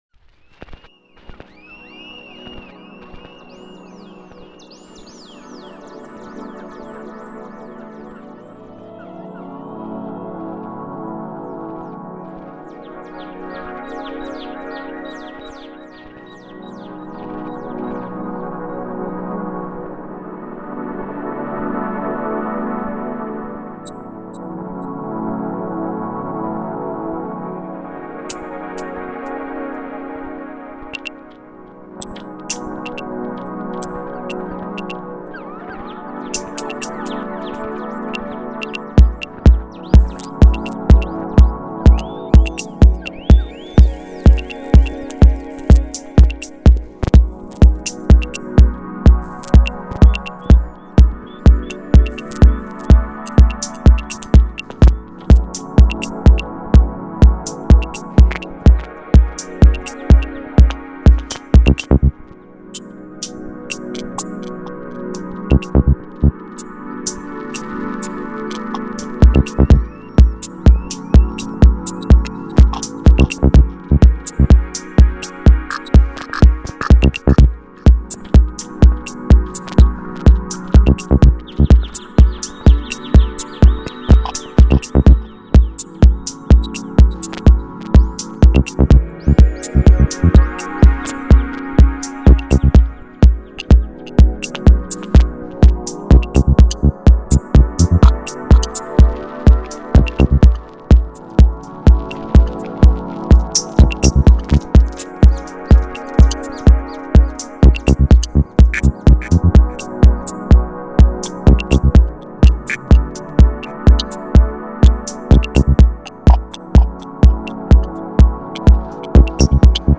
DJ Set: Deep-House, Dub-Techno